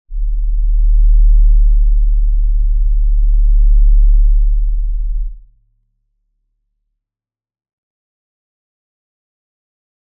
G_Crystal-D1-mf.wav